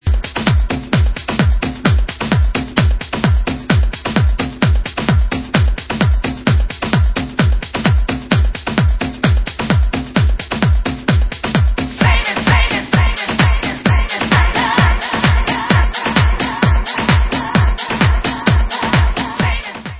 Vocal house